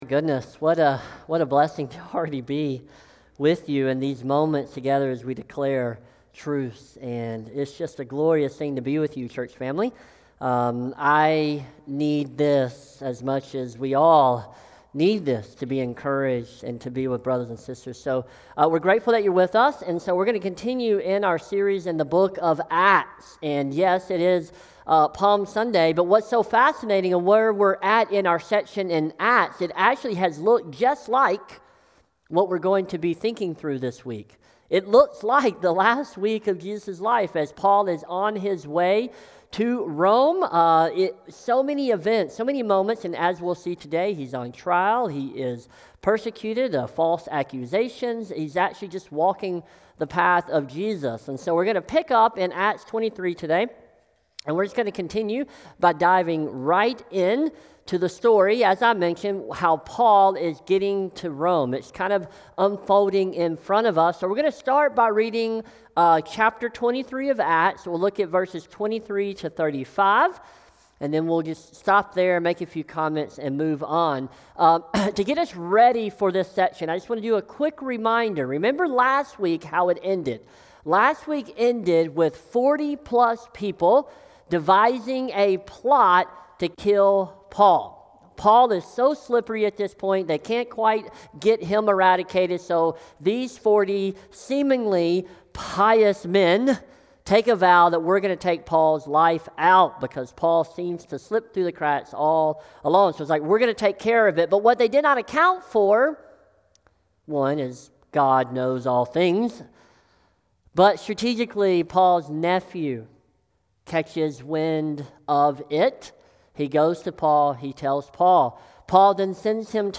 SERMON | Acts 23:23-24:27 | Just Judgement feeds Just People | Light in the Desert Church